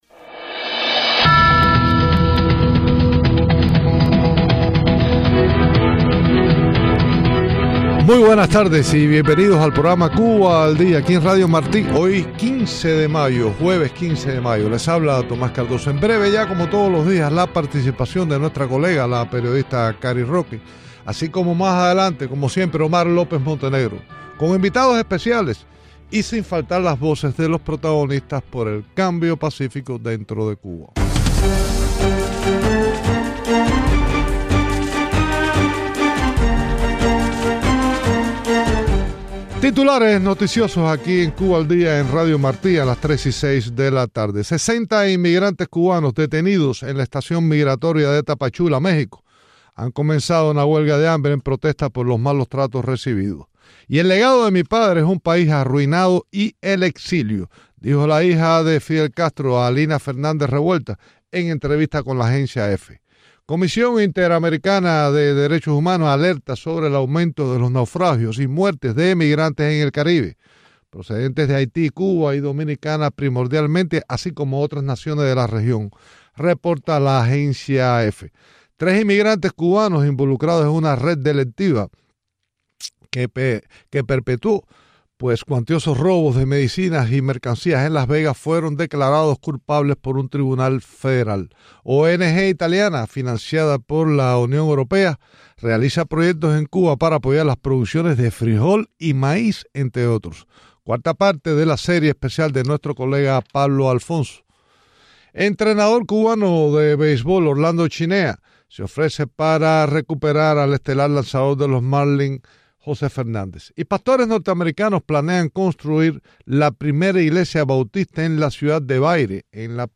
Entrevistas con el embajador Myles Frechette y Berta Soler en Cuba.